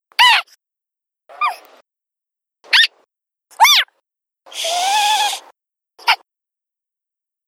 whackaMole_randomSqueak_1.wav